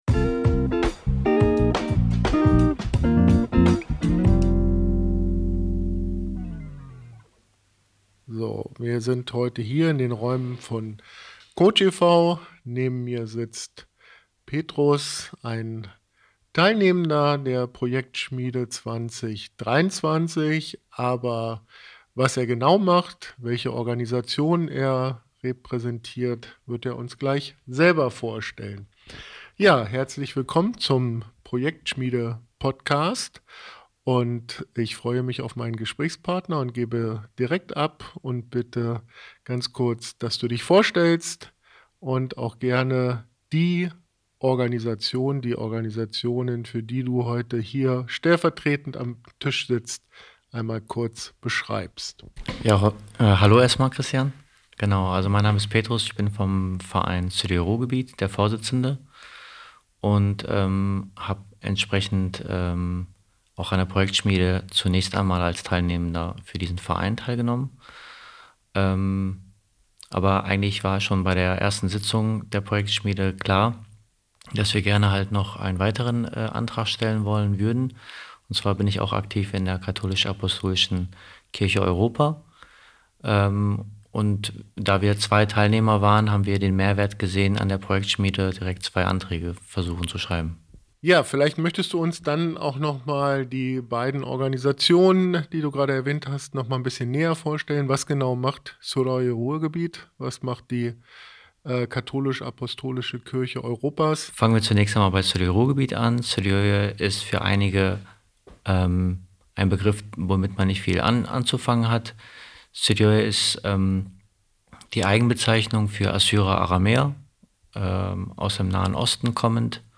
Das und tolle Möglichkeiten der Partizipation im Verein hört ihr in unserer dritten Episode im Projektschmiede Podcast. Das Interview